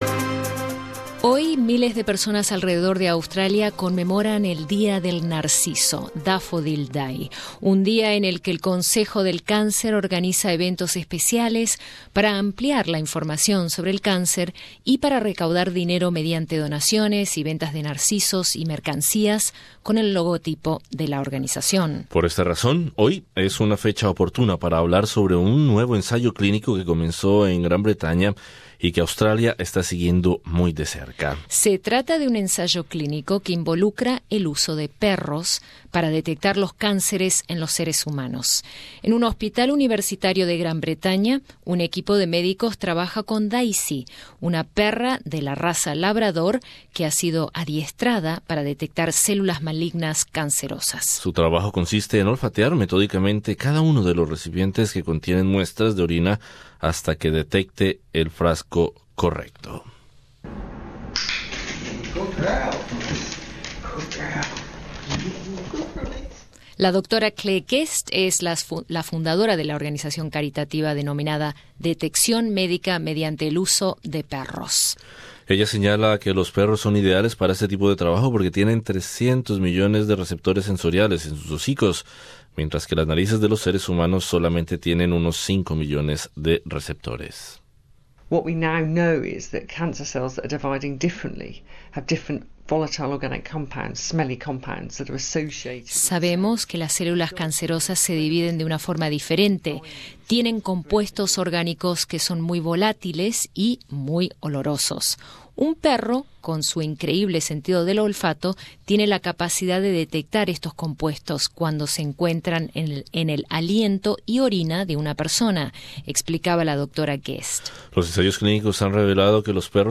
Australia observa con interés los ensayos clínicos de un hospital universitario de Gran Bretaña donde un equipo de médicos especializados está trabajando con Daisy, una perra de la raza Labrador, que ha sido adiestrada para detectar cáncer de próstata. Escucha el informe.